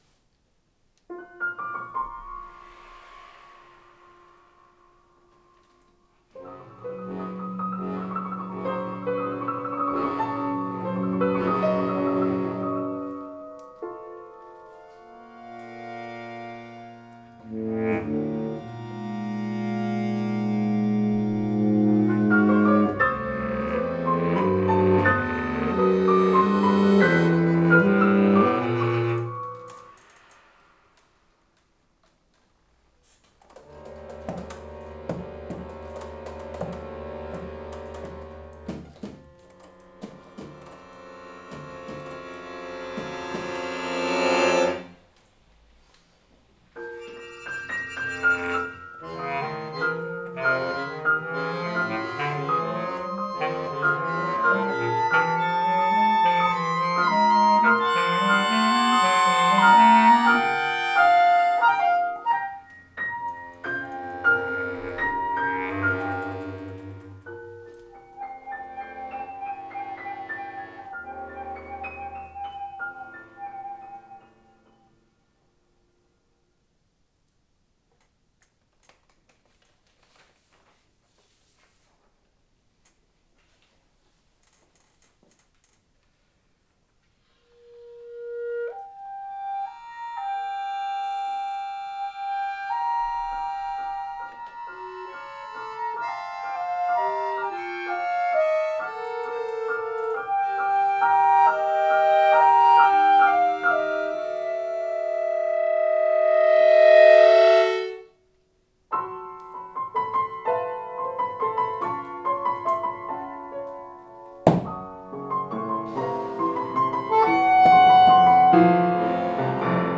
Retour dans la forêt: Une création musicale inspirée par « Retour dans la forêt », chapitre XXXIV de Harry Potter et les Reliques de la Mort.
œuvre pour quatuor (accordéon, piano, clarinette, saxophone)